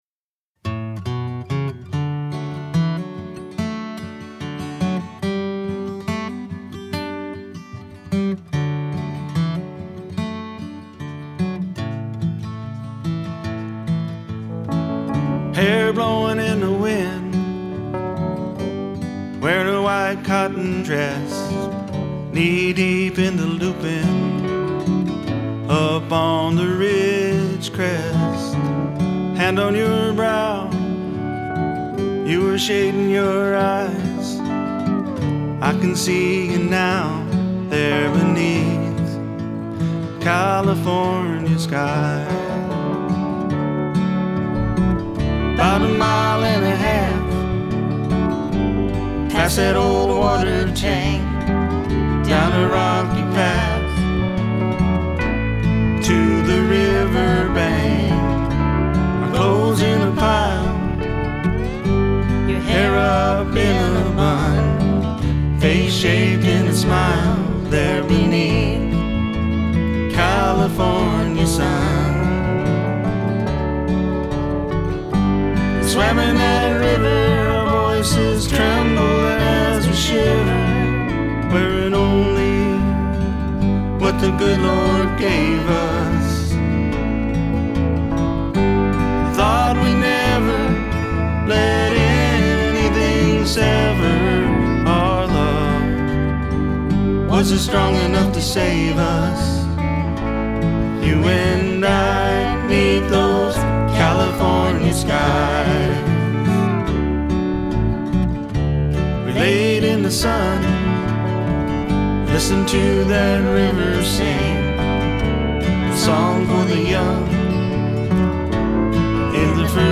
vocals, Acoustic guitar
MSA pedal steelel